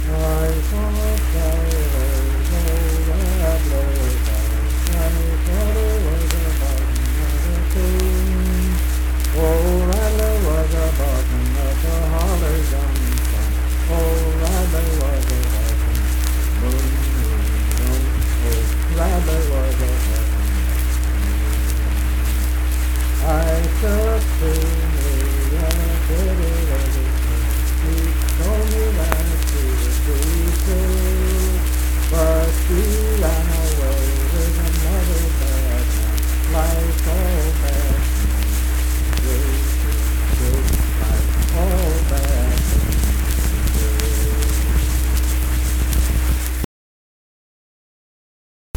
Unaccompanied vocal music
Verse-refrain 2(5).
Voice (sung)
Webster County (W. Va.)